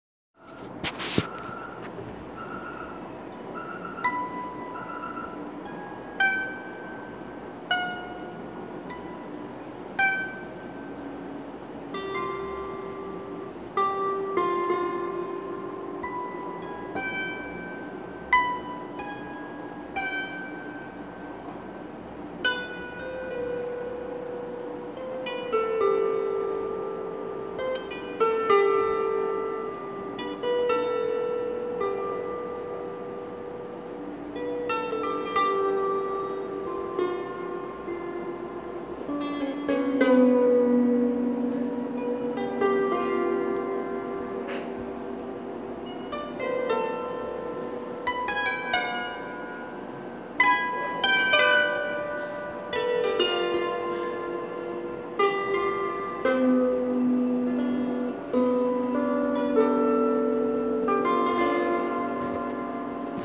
Swar-Mandal – The Swar-Mandal or Indian harp is sometimes used as an accompanying instrument for vocal Indian classical music.
Following is a short (1 minute) recording of an experimental piece played on this swarmandal.
swar-mandal-by-tk.m4a